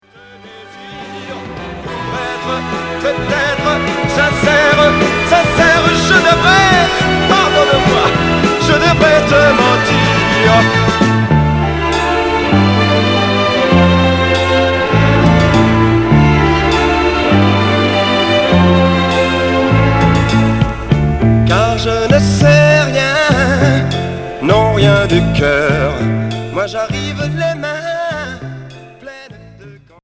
Chanteur 60's Sixième 45t retour à l'accueil